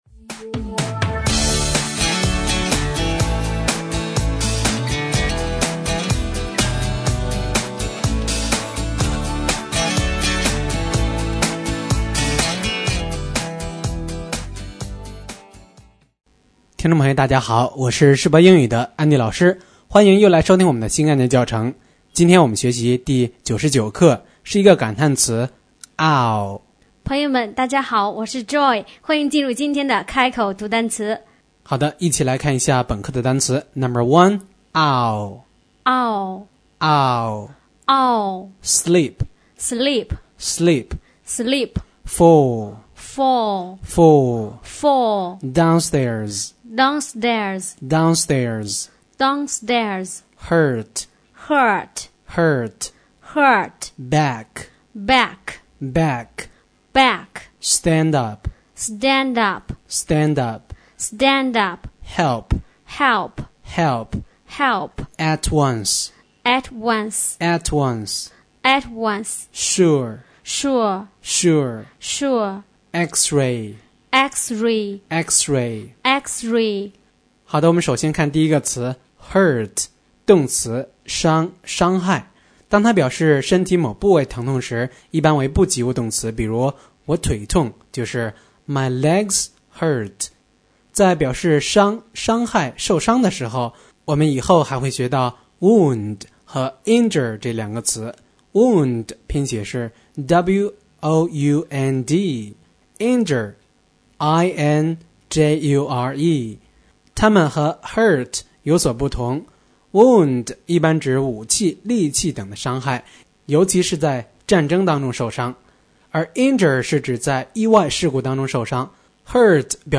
新概念英语第一册第99课【开口读单词】